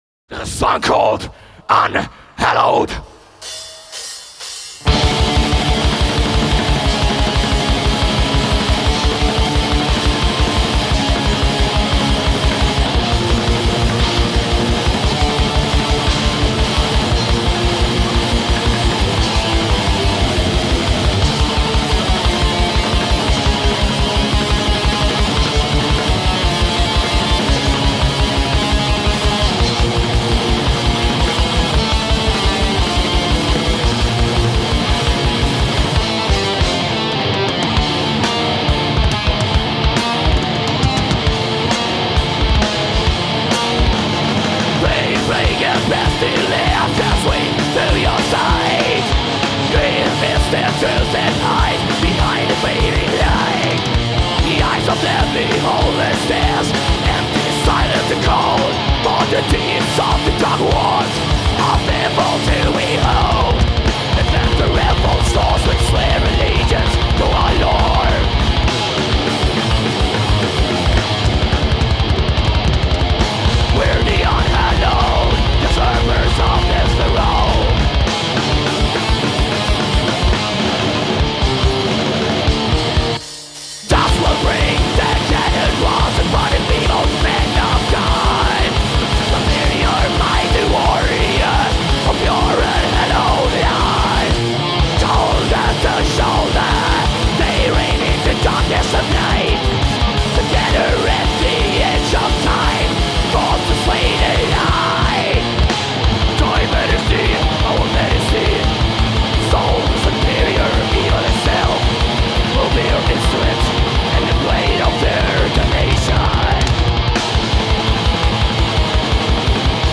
Live
Metal